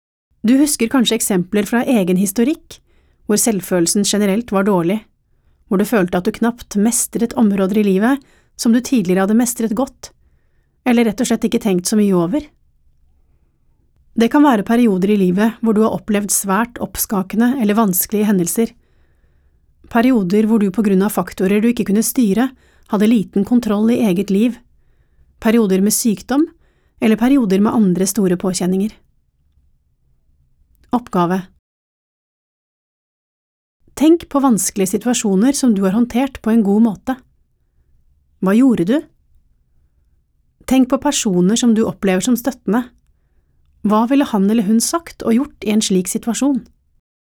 Avspenningsøvelse / oppmerksomhetsøvelse:
Kroppslig, progressiv avspenning: